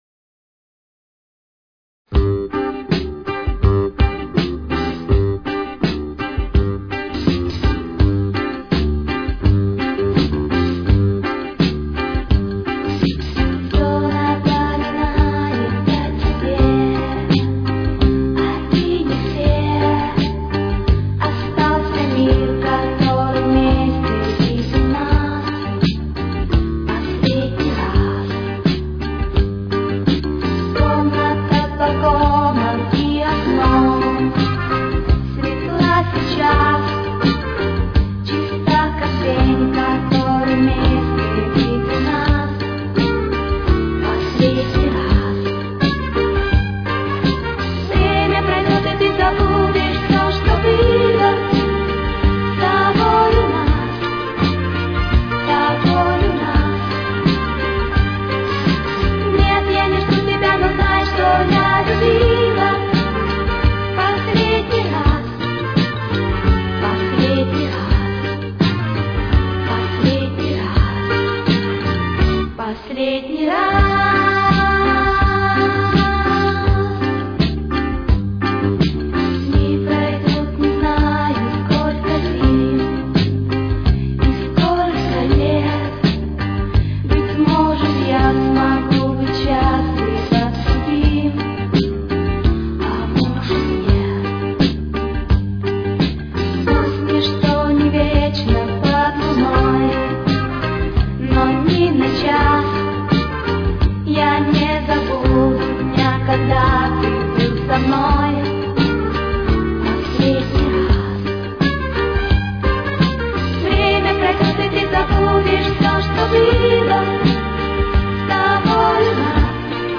Тональность: Соль минор. Темп: 86.